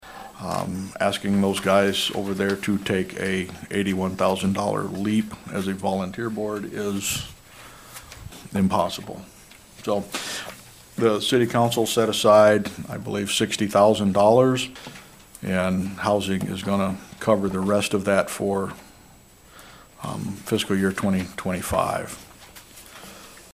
Also at Wednesday’s council meeting: